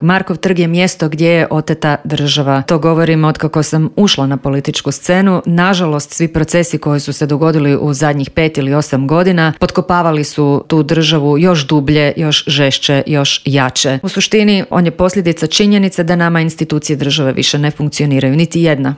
ZAGREB - Prijepori oko Ivana Turudića i procedure izbora glavnog državnog odvjetnika, izmjene Kaznenog zakona, veliki prosvjed oporbe, sindikalni prosvjedi i potencijalni štrajkovi neke su od tema o kojima smo u Intervjuu Media servisa razgovarali sa saborskom zastupnicom iz Stranke s imenom i prezimenom Dalijom Orešković.